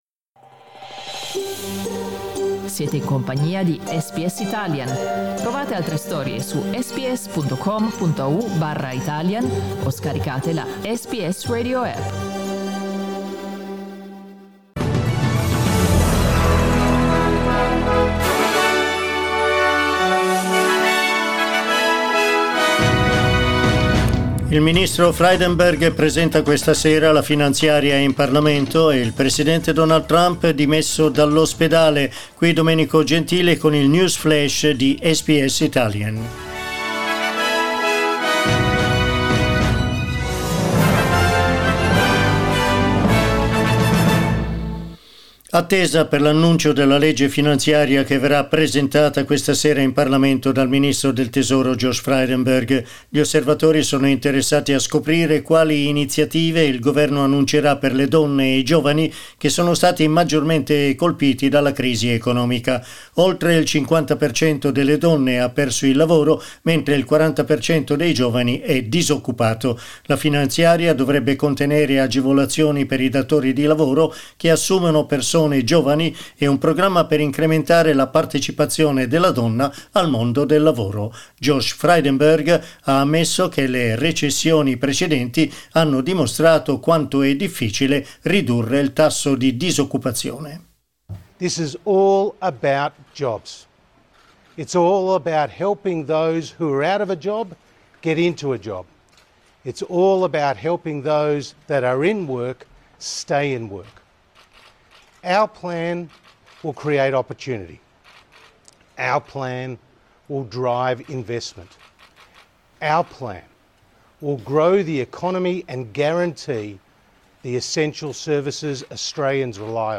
Our news update in Italian.